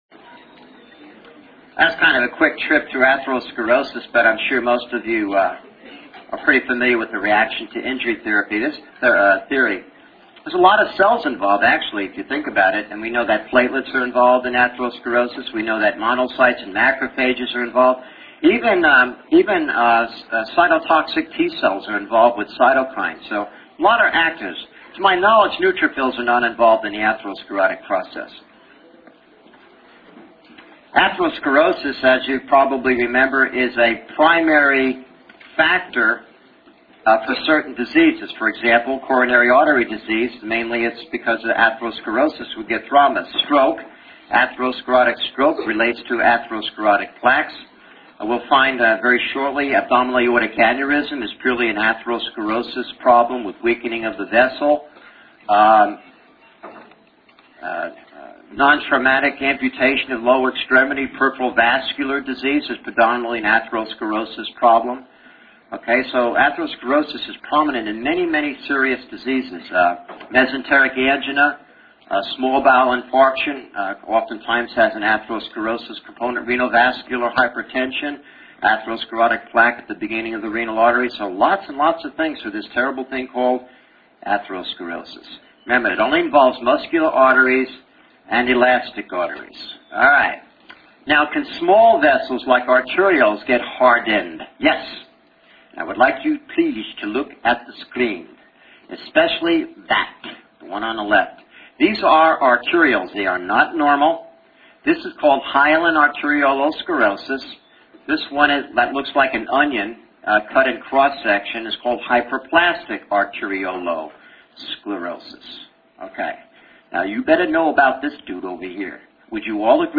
Audio Lectures